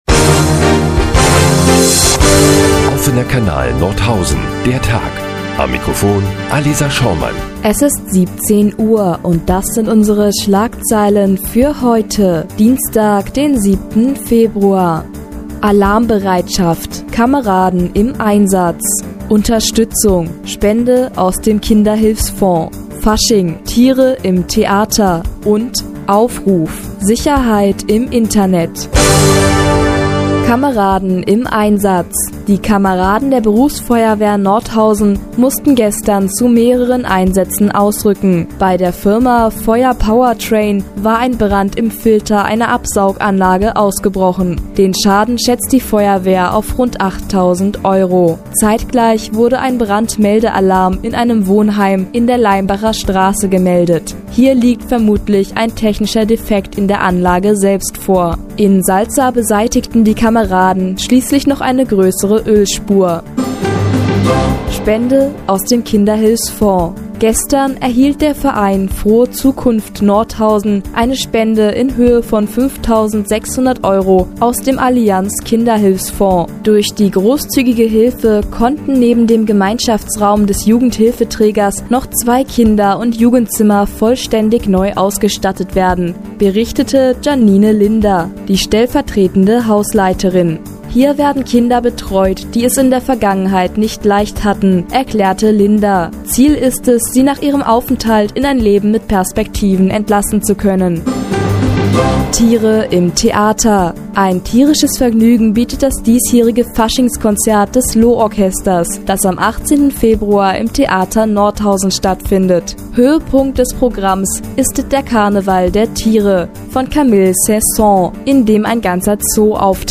Die tägliche Nachrichtensendung des OKN ist nun auch in der nnz zu hören.